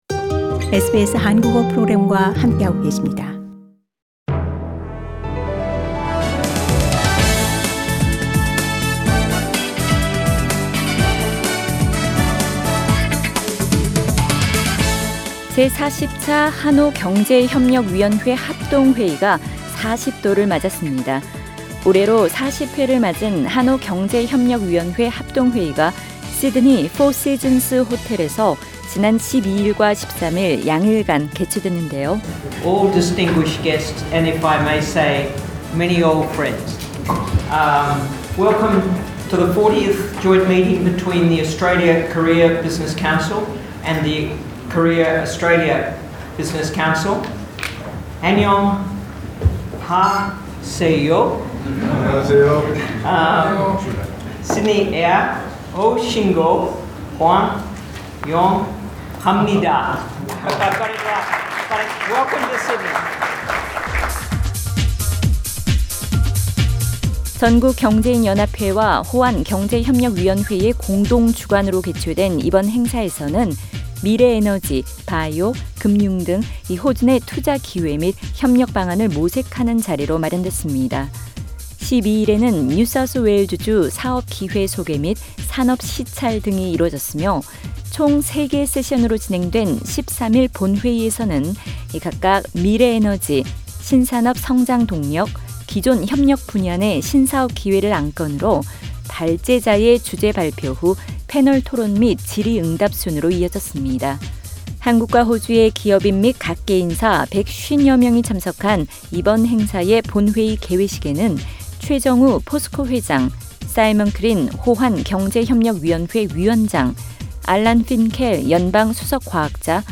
Hon Simon Crean, Chair of the Australia-Korea Business Council Source: SBS [The Full interview with Hon Simon Crean, Chair of the Australia-Korea Business Council, is available on the podcast above] Share